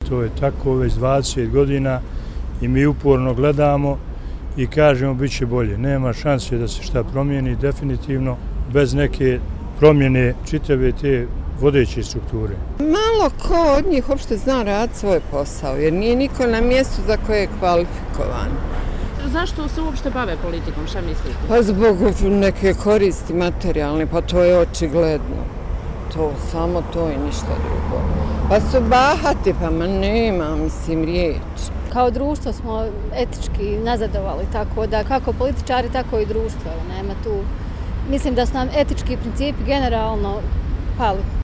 Građani Sarajeva